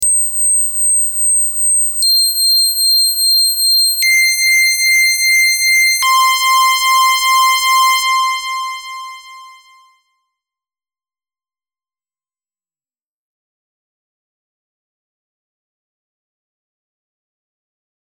вот тут еле еле заметный алиасинг только на самой высокой ноте слышу.
Это PWM Вложения SH-101 vs Plug Out AA.mp3 SH-101 vs Plug Out AA.mp3 710,1 KB · Просмотры: 337